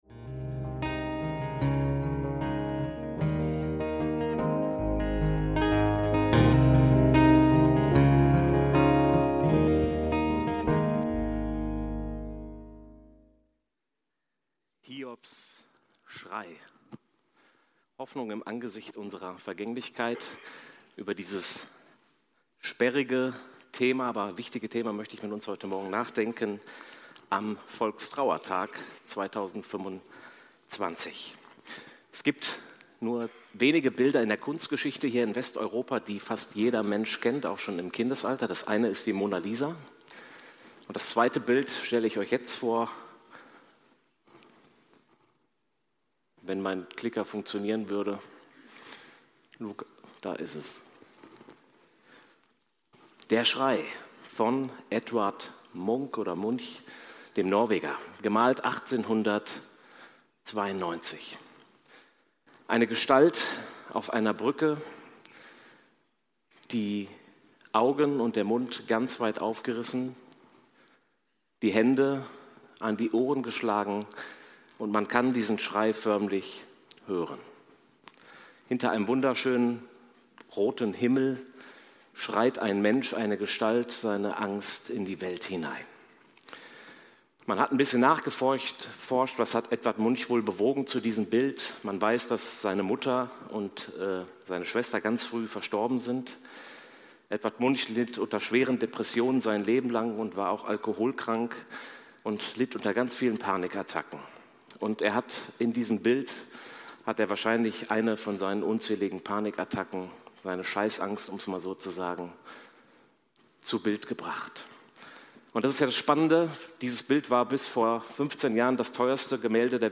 Hiobs Schrei – Predigt vom 16.11.2025